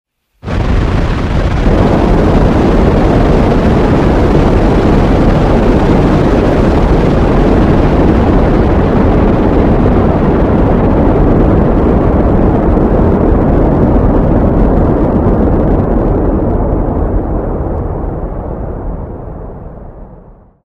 На этой странице собраны звуки космических ракет: от рева двигателей при старте до гула работы систем в открытом космосе.